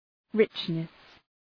Προφορά
{‘rıtʃnıs}